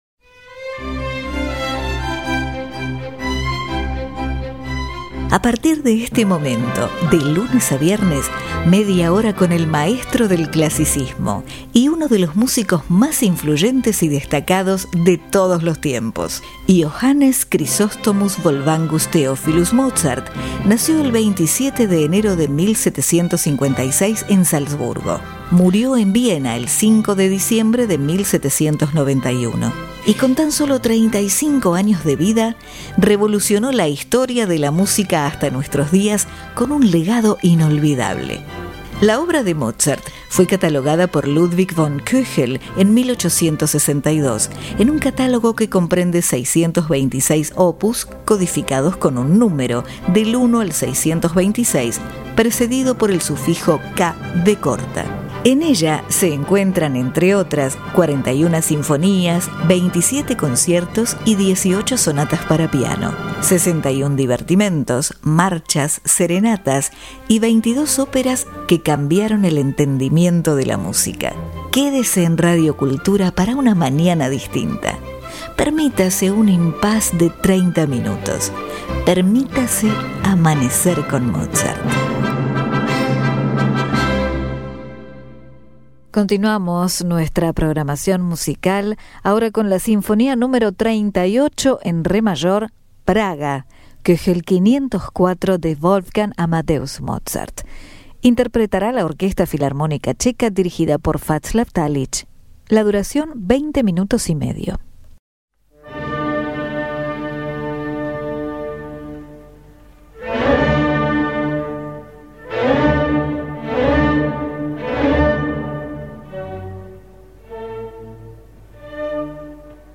Sinfonía
Orquesta Filarmónica Checa Vaclav Talich
En La Menor
Dinu Lipatti (Piano)